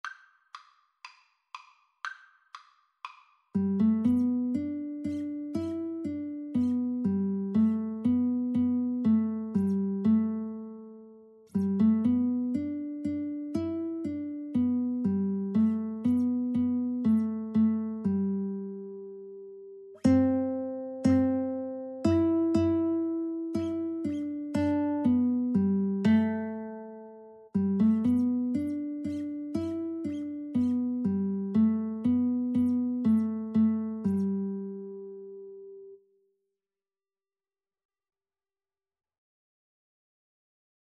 A traditional song from the US.
Andante =120
Arrangement for Guitar Duet
G major (Sounding Pitch) (View more G major Music for Guitar Duet )